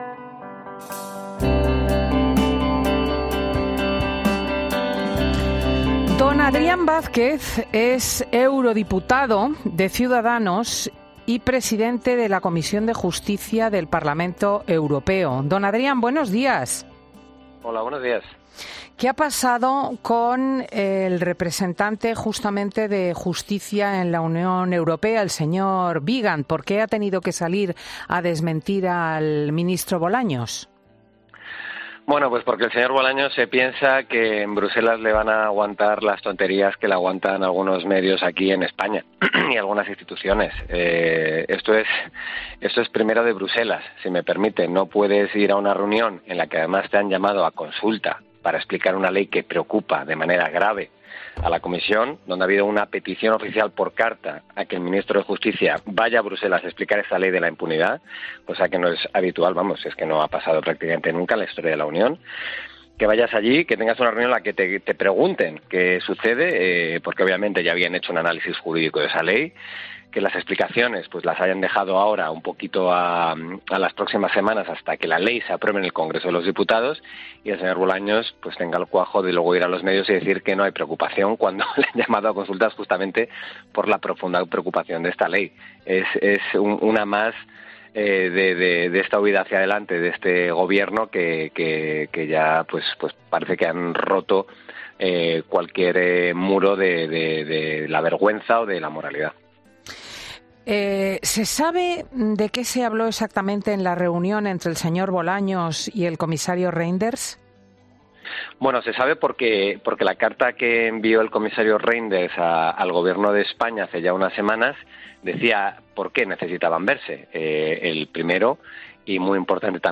Adrián Vázquez, presidente de la Comisión de Justicia del Parlamento Europeo, en Fin de Semana COPE